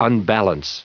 Prononciation du mot unbalance en anglais (fichier audio)
Prononciation du mot : unbalance
unbalance.wav